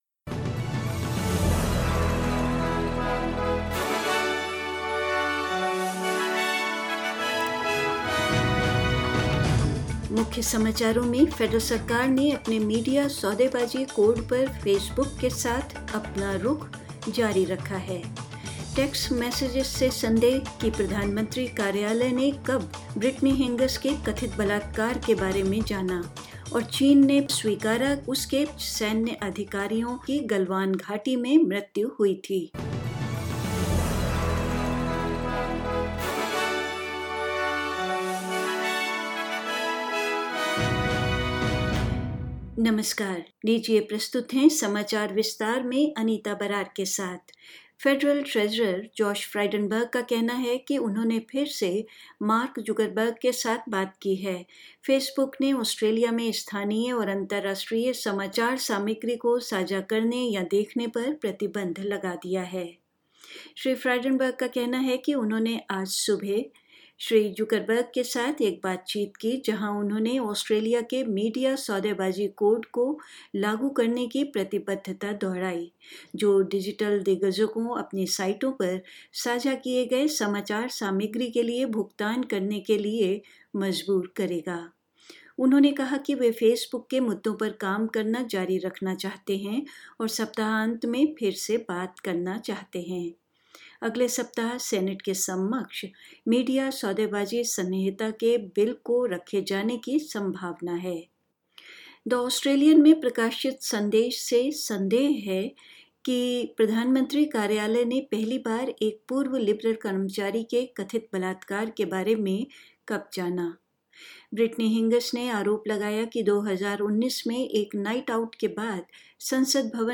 News in Hindi: Stand-off with Facebook over its media bargaining code continues